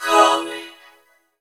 COMIN VOX -L.wav